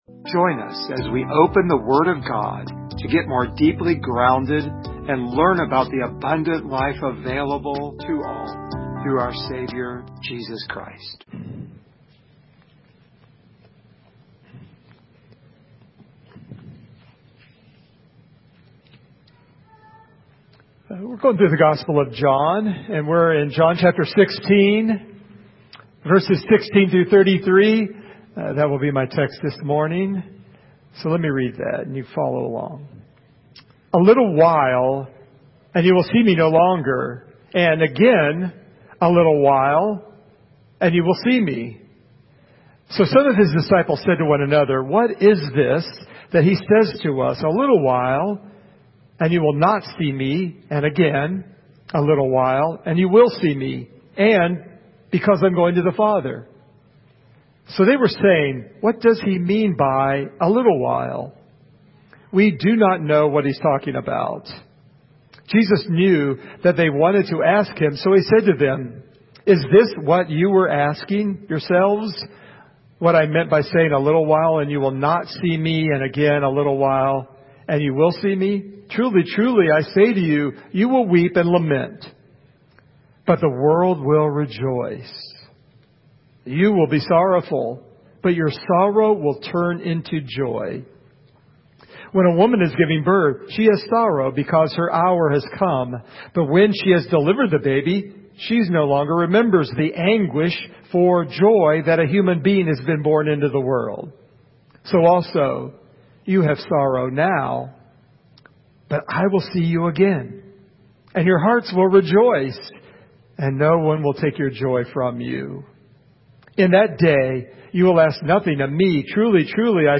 Passage: John 16:16-33 Service Type: Sunday Morning